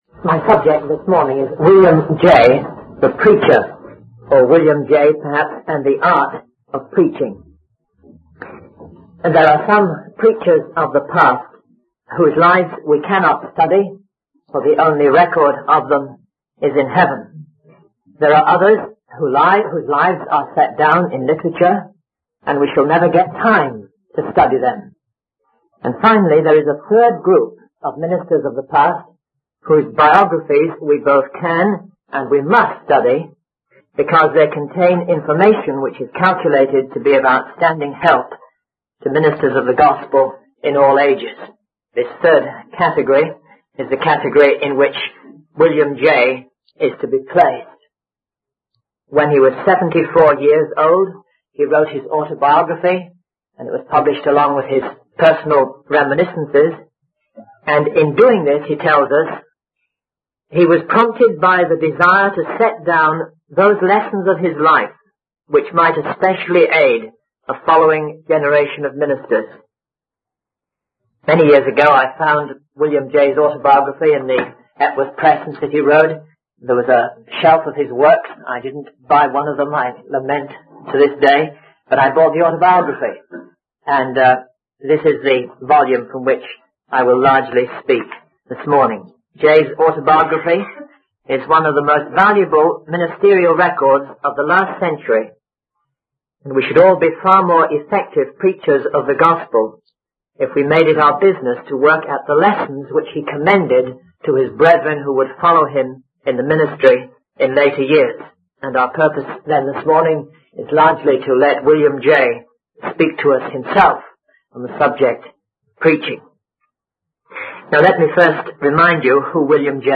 In this sermon transcript, the speaker emphasizes the importance of structuring sermons with clear divisions to aid in the retention and understanding of the message. He argues that without a well-organized sermon, the truth of God's word can easily be forgotten.